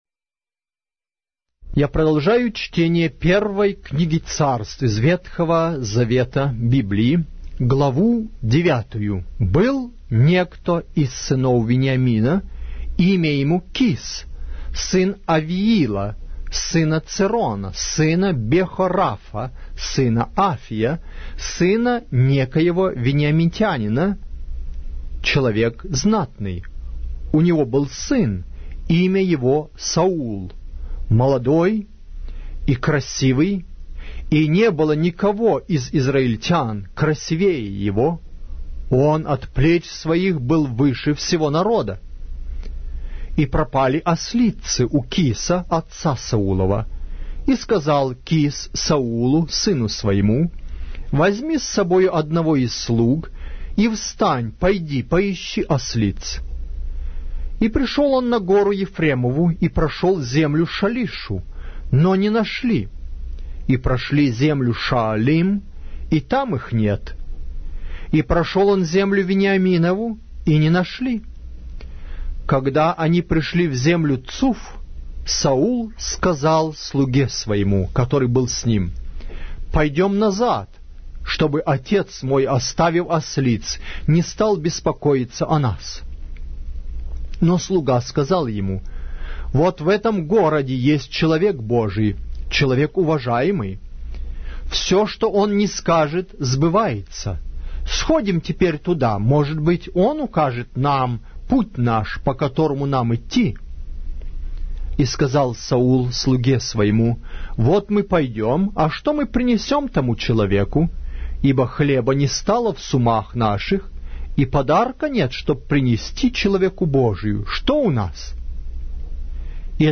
Глава русской Библии с аудио повествования - 1 Samuel, chapter 9 of the Holy Bible in Russian language